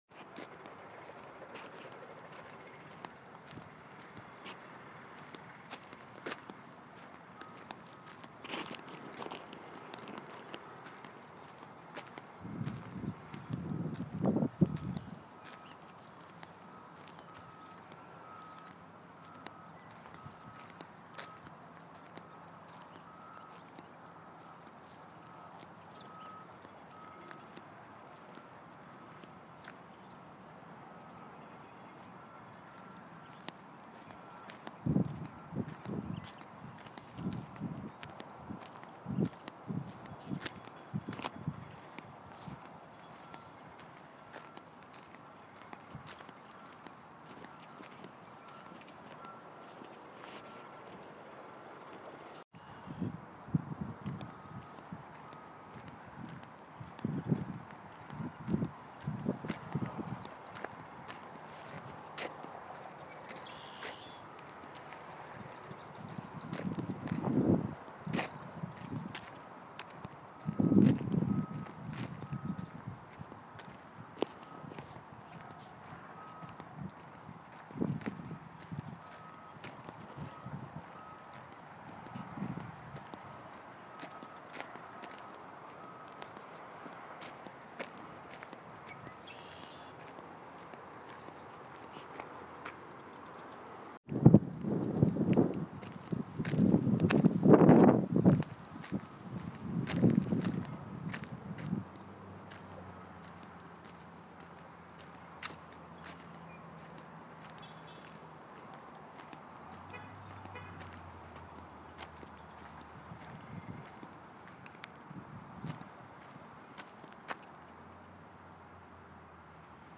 Sunny, around 1PM, so easy to get around for sun and the lack of some loud noises (like leafblowers or schoolbuses).